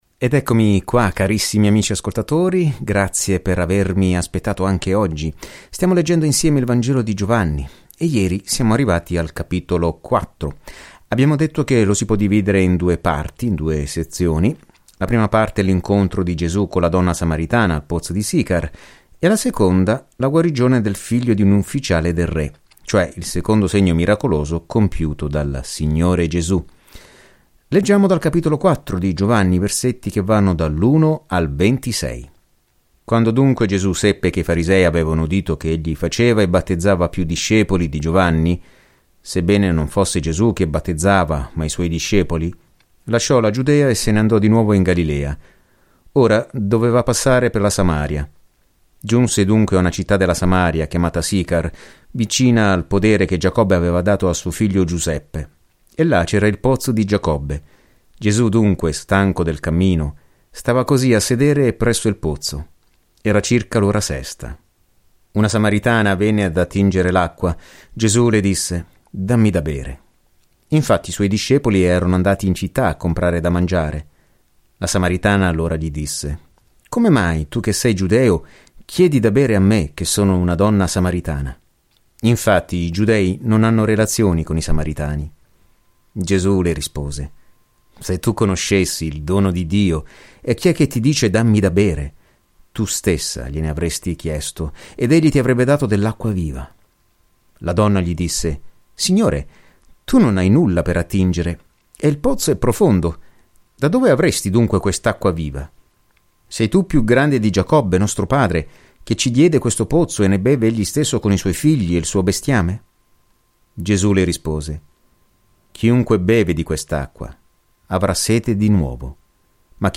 Viaggia ogni giorno attraverso Giovanni mentre ascolti lo studio audio e leggi versetti selezionati della parola di Dio.